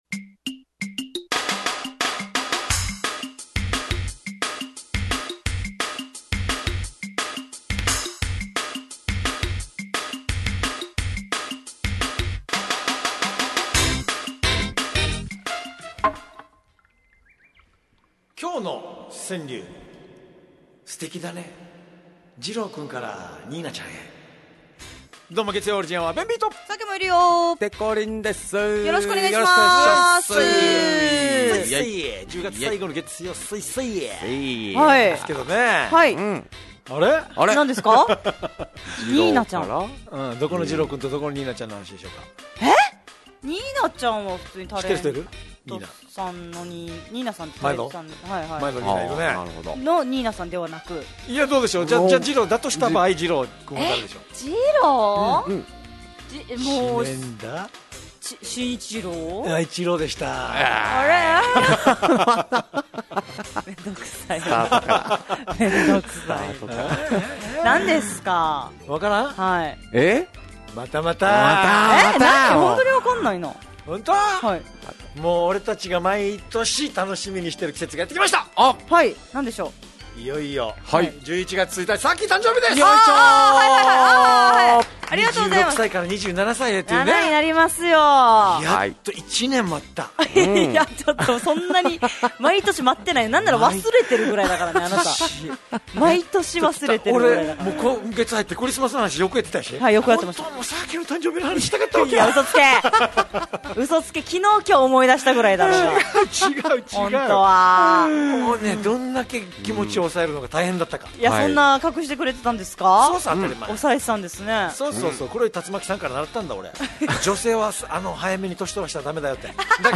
fm那覇がお届けする沖縄のお笑い集団・オリジンメンバー出演のバラエティ番組のオリジンアワー